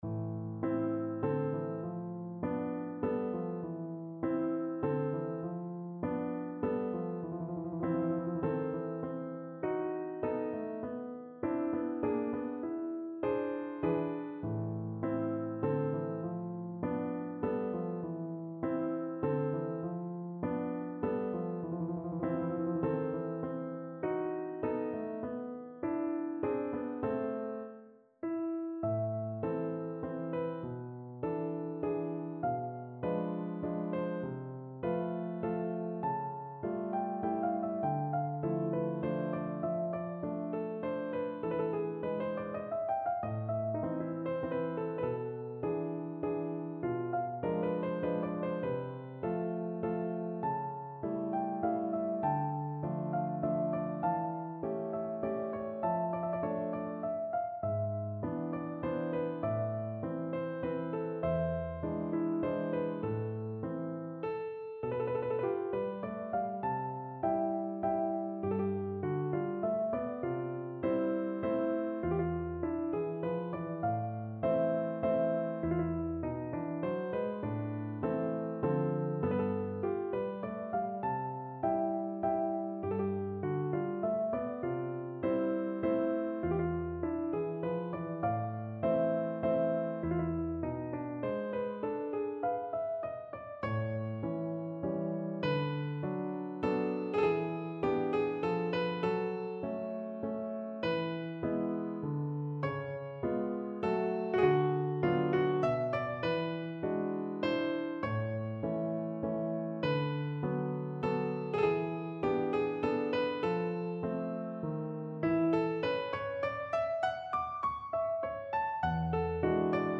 Free Sheet music for Piano
No parts available for this pieces as it is for solo piano.
3/4 (View more 3/4 Music)
C major (Sounding Pitch) (View more C major Music for Piano )
Lento
Piano  (View more Intermediate Piano Music)
Classical (View more Classical Piano Music)